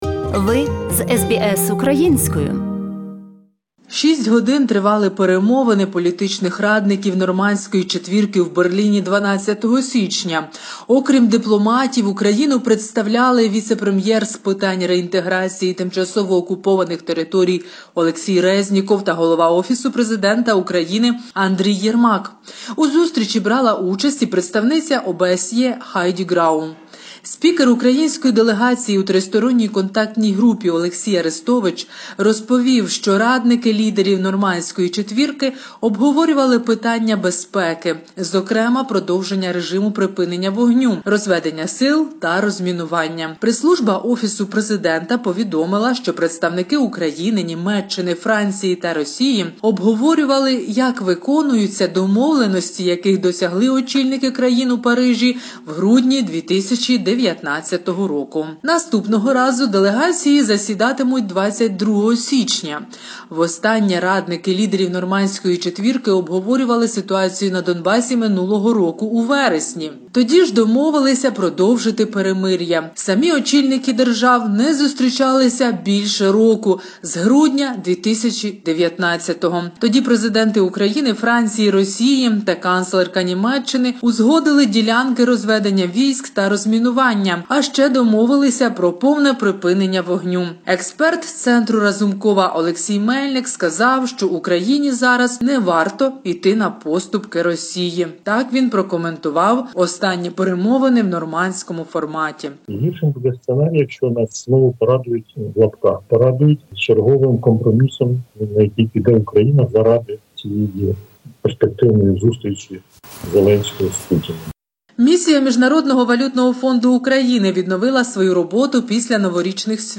огляді новин